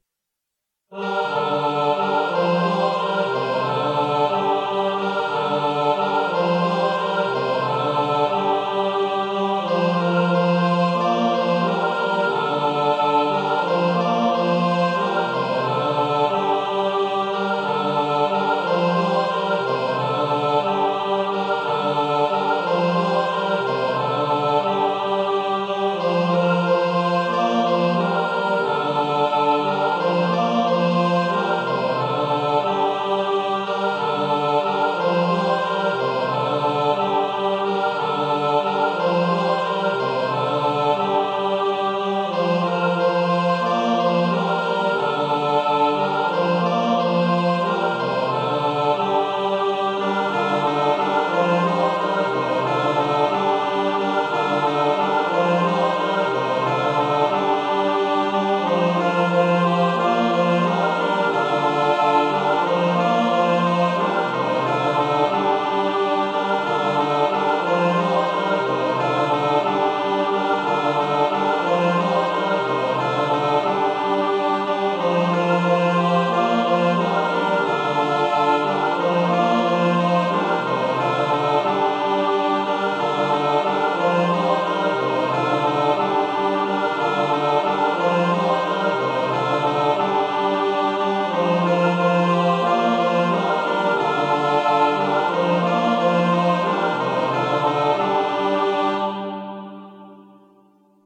pro smíšený sbor